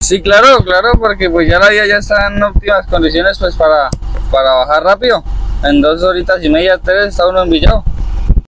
conductor de transporte pesado.